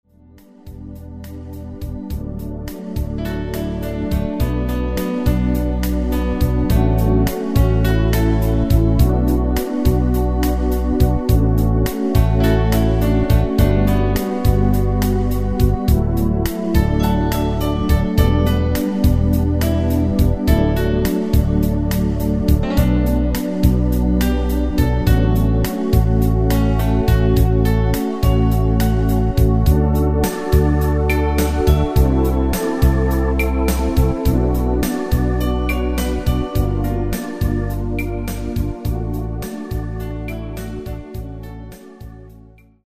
DEMO MP3 MIDI